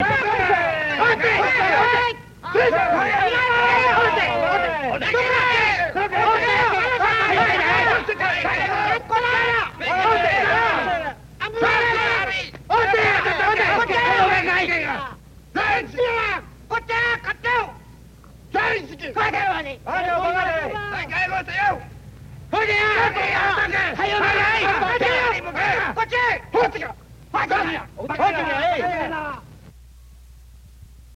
Crowd Japanese Yelling Sort Of Bonzai Like